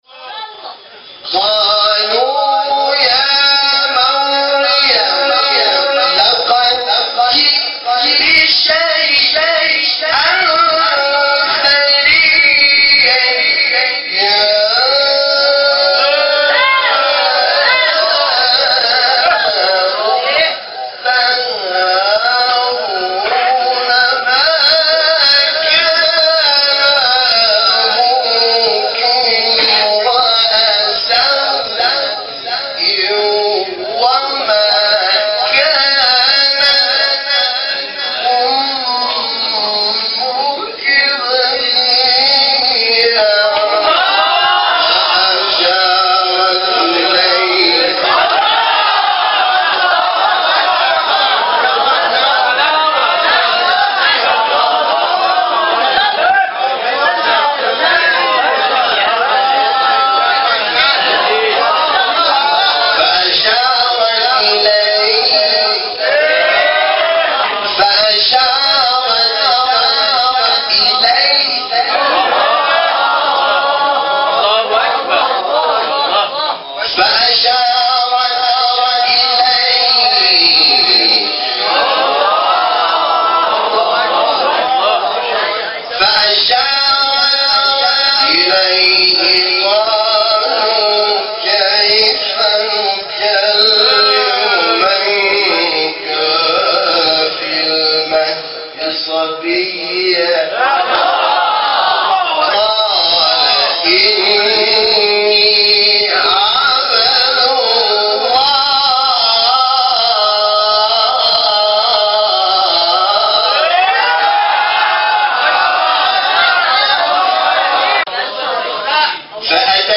• استاد حامد شاکنژاد, حامد شاکرنژاد, سوره مریم, تلاوتن سوره مریم از استاد حامد شاکرنژاد